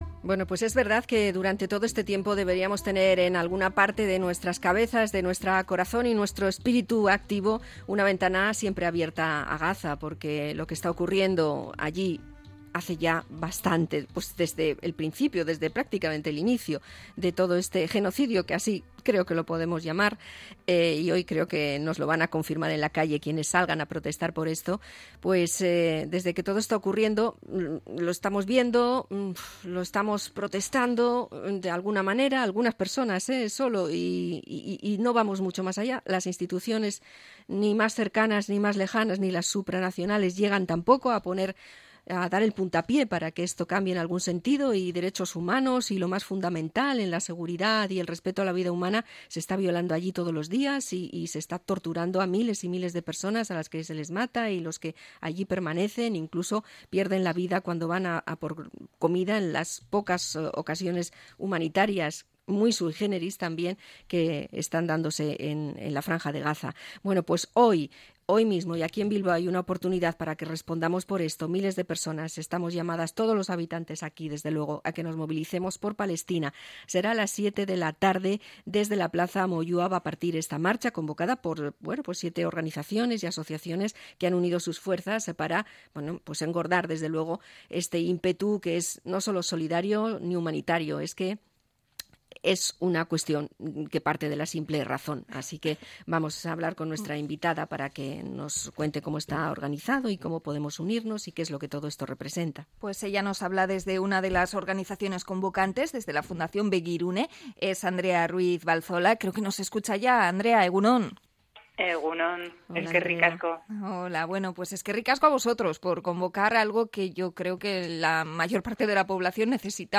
INT.-PALESTINA-26-JUNIO.mp3